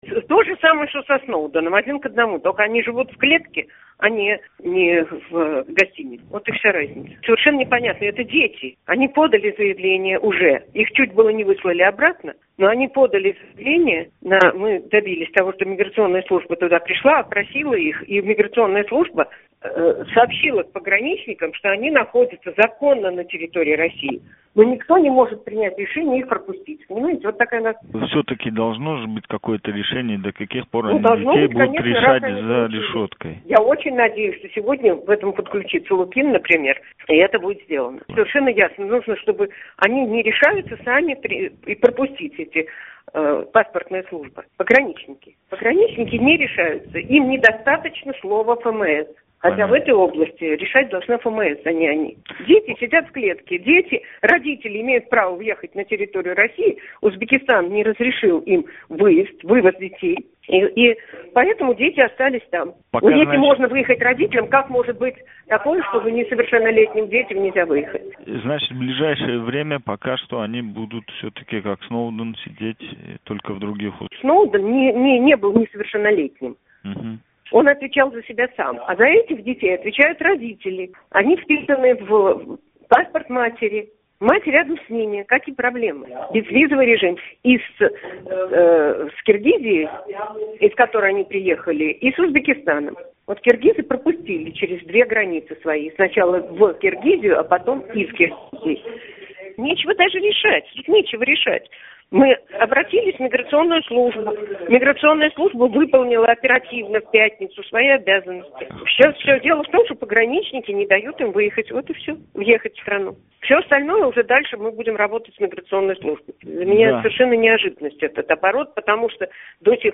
Ганнушкина билан суҳбат